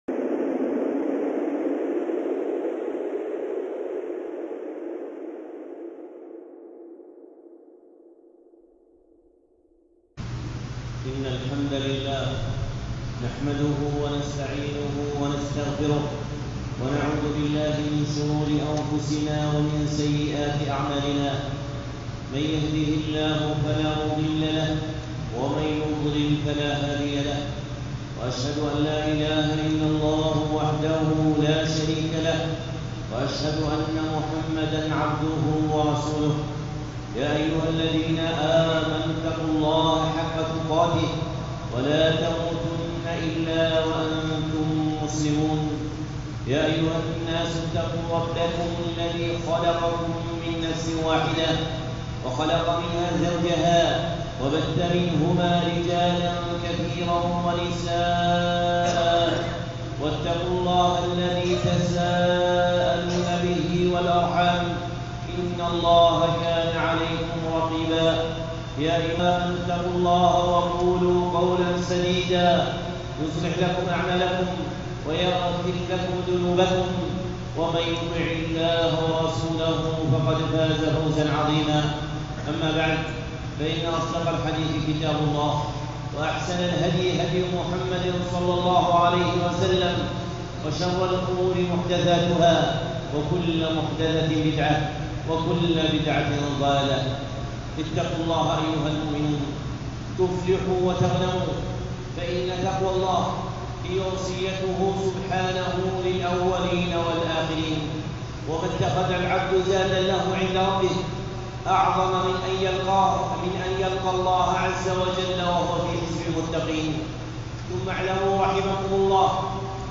خطبة (يا عباد الله اثبتوا)